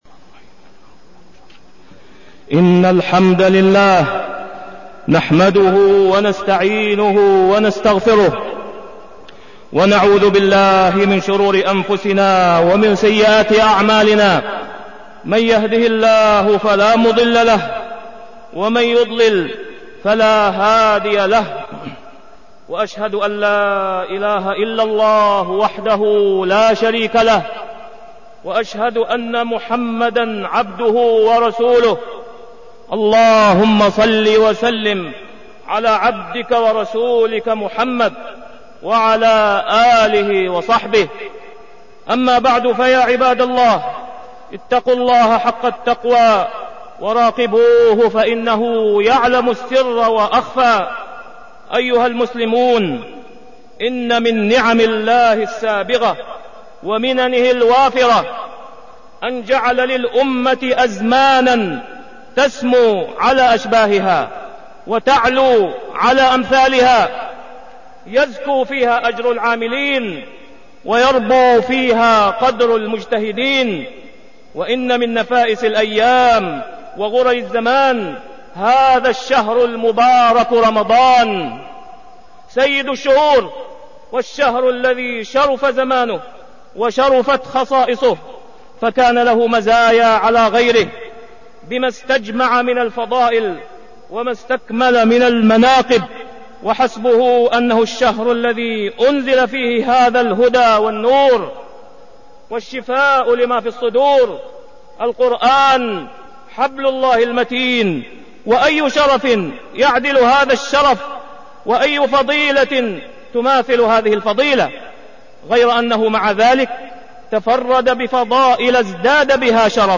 تاريخ النشر ١١ رمضان ١٤١٨ هـ المكان: المسجد الحرام الشيخ: فضيلة الشيخ د. أسامة بن عبدالله خياط فضيلة الشيخ د. أسامة بن عبدالله خياط فضائل رمضان